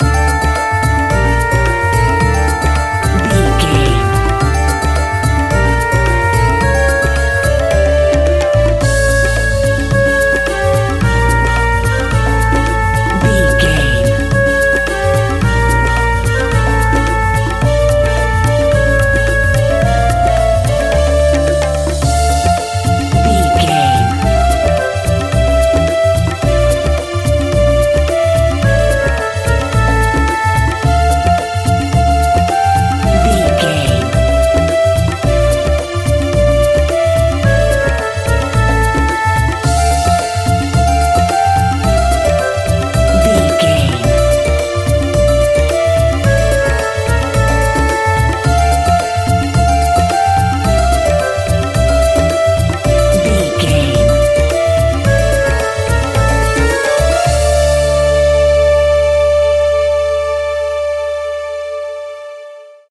Aeolian/Minor
D
World Music
percussion